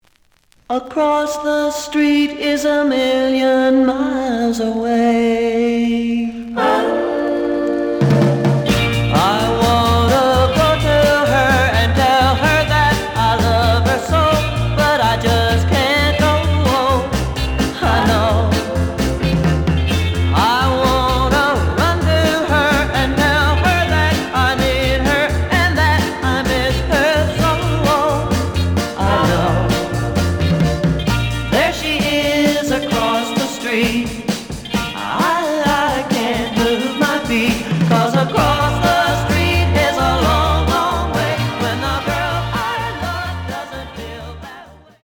The audio sample is recorded from the actual item.
●Format: 7 inch
●Genre: Rock / Pop
Slight edge warp.